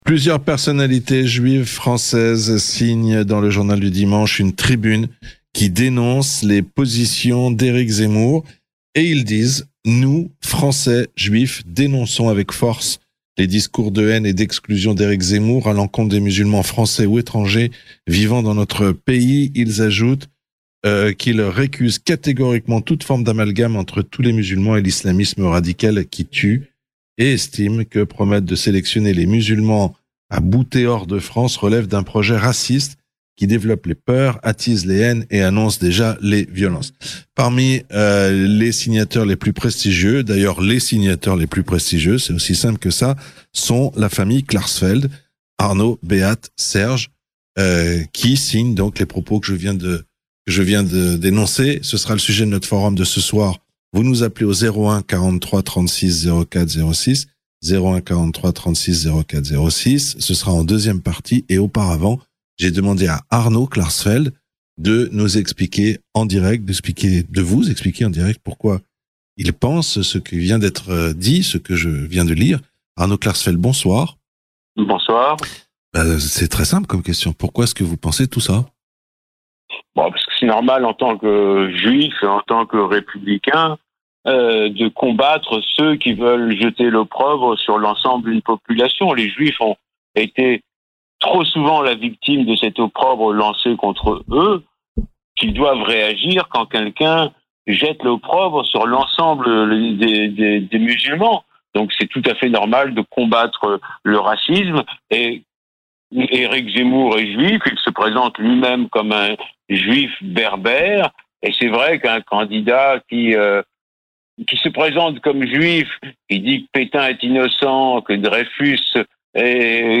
Parmi les initiateurs de cette pétition : Arno Klarsfeld. Interview